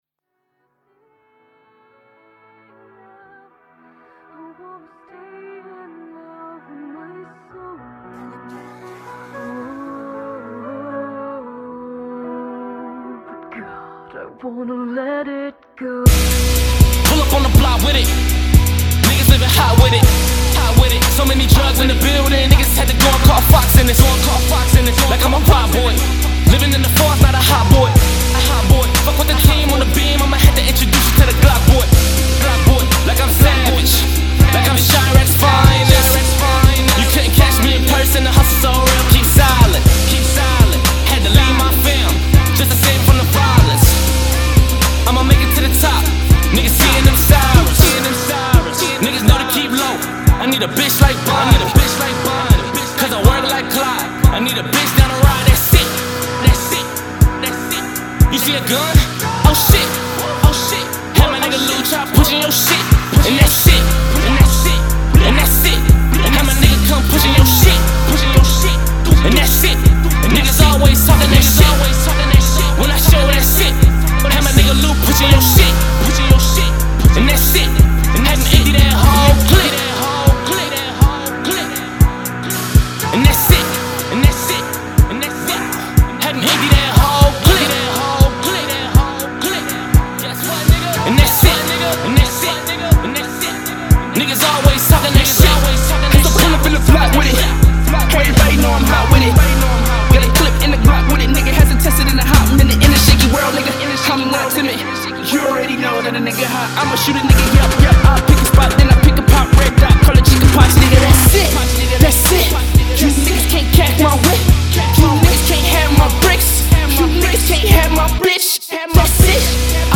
Im not finished with the track.i cut breaths an all that mixed up a little bit. some compression an this an that. just an opinion on anything i should fix. not word wise mixing wise.